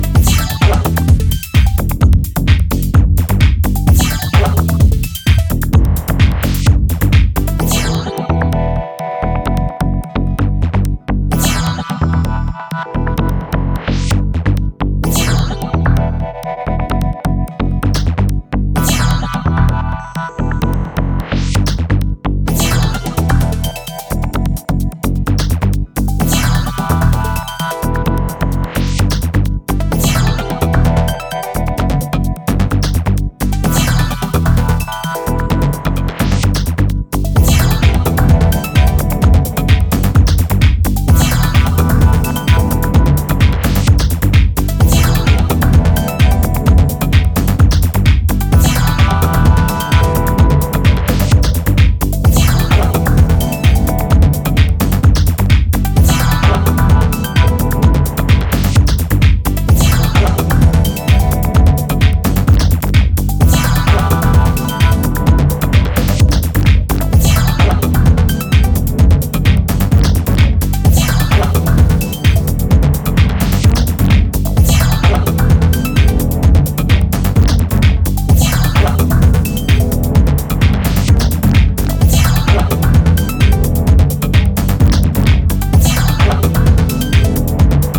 歪んだベースラインによる低重心のグルーヴとトリッピーな音色の応酬が続く